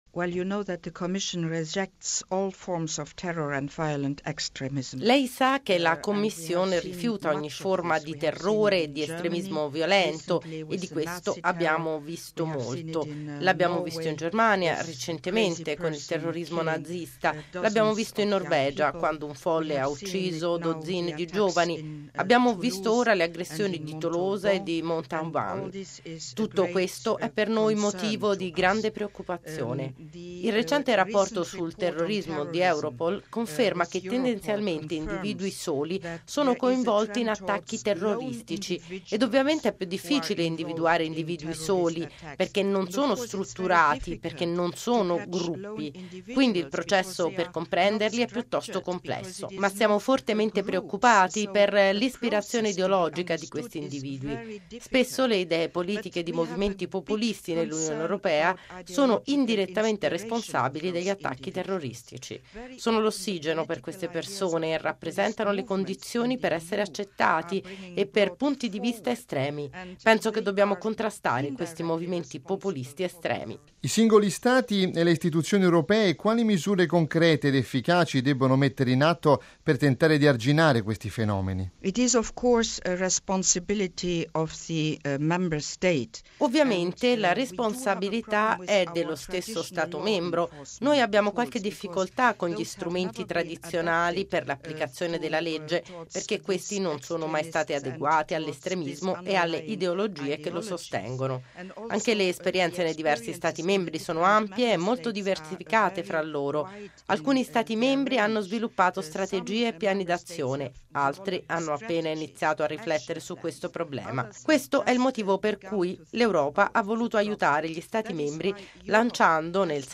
Integrare le minoranze in Europa: intervista con Viviane Reding